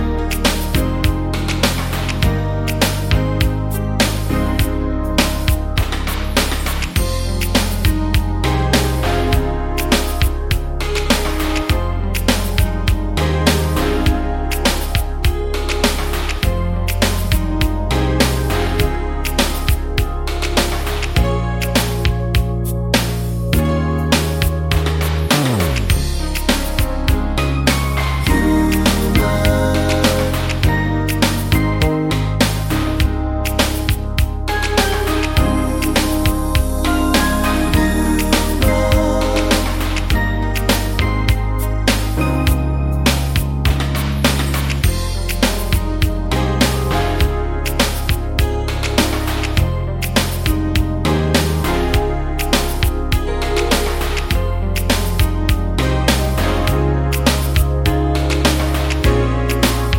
Duet Version Pop (1980s) 3:53 Buy £1.50